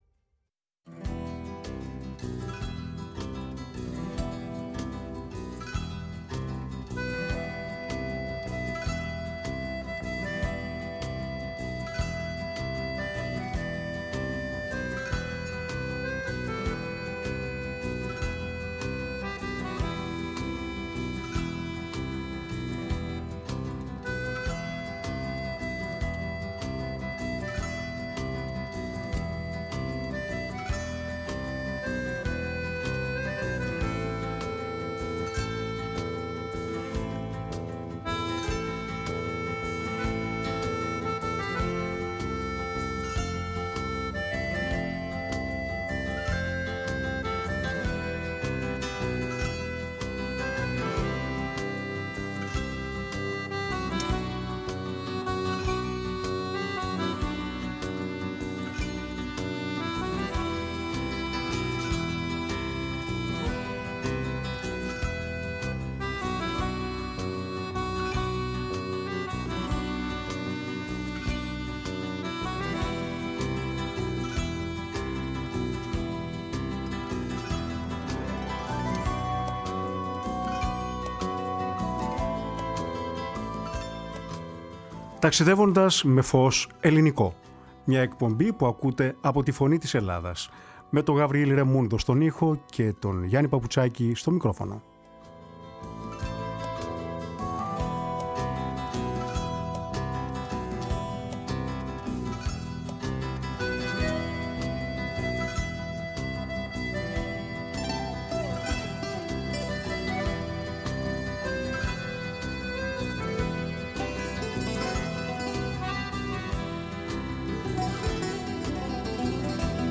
Ένα αφιέρωμα στα μελοποιημένα τραγούδια